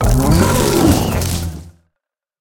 Cri de Chongjian dans Pokémon HOME.